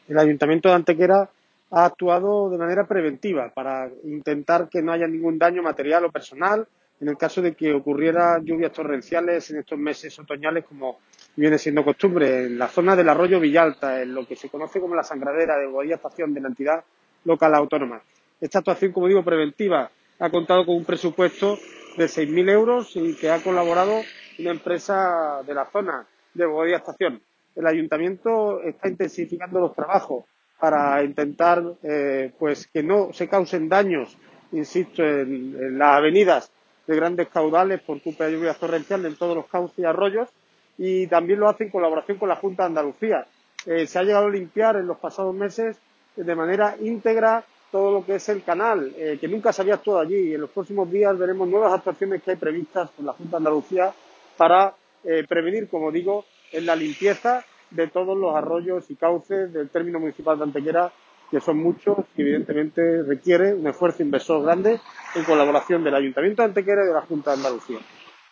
El concejal delegado de Obras del Ayuntamiento de Antequera, José Ramón Carmona, informa de la reciente inversión de 6.000 euros en una actuación preventiva de limpieza y mantenimiento del cauce del arroyo Villalta a su paso por el casco urbano de la Entidad Local Autónoma de Bobadilla Estación.
Cortes de voz